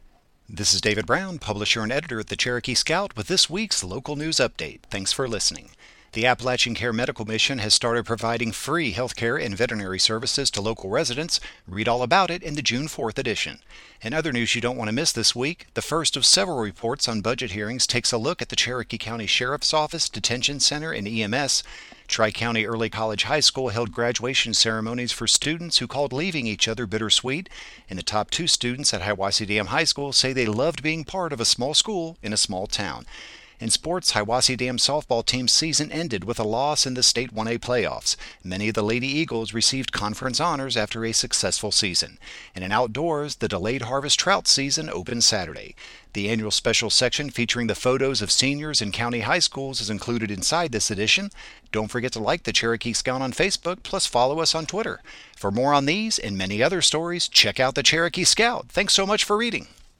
Cherokee Scout News Update